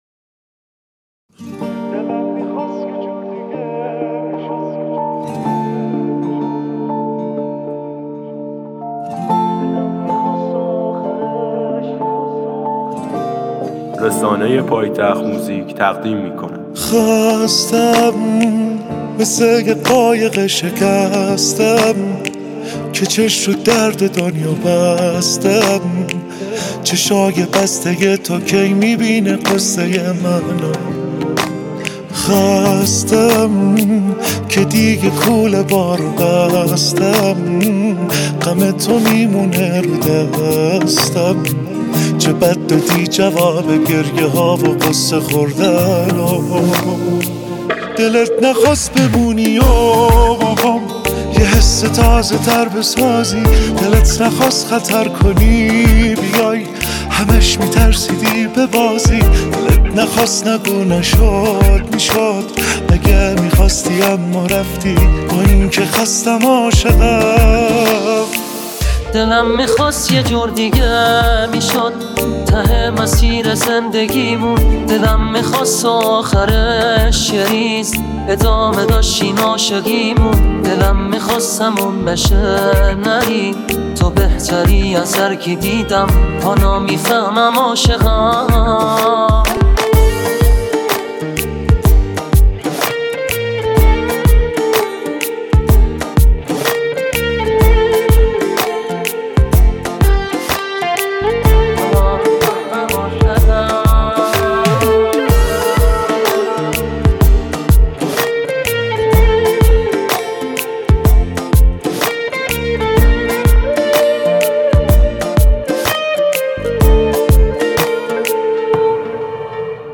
آهنگ هوش مصنوعی